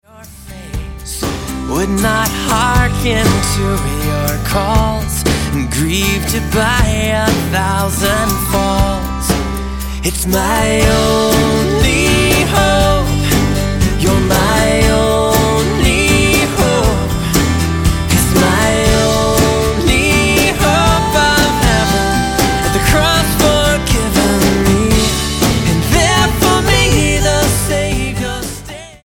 STYLE: Roots/Acoustic
signature harmonies and driving guitars